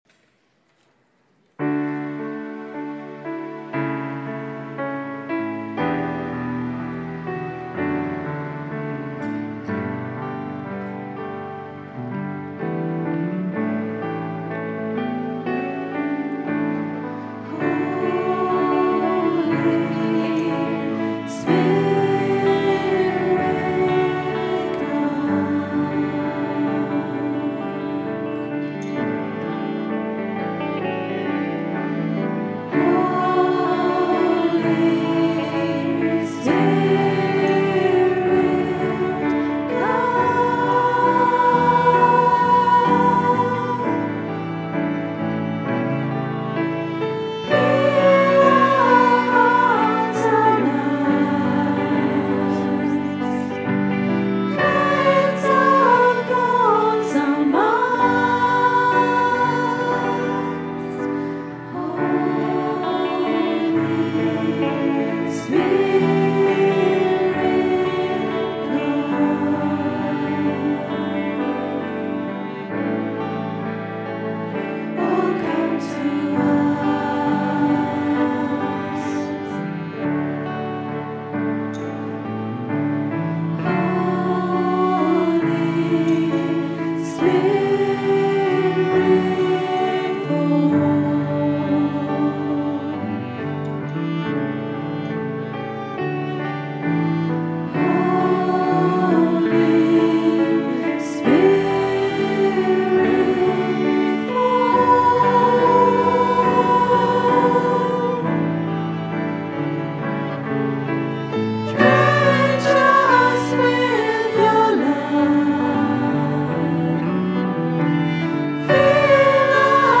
Recorded at 10 am Mass, 7th October 2012 on a Zoom H4 digital stereo recorder.